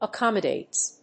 /ʌˈkɑmʌˌdets(米国英語), ʌˈkɑ:mʌˌdeɪts(英国英語)/
フリガナアカマデイツ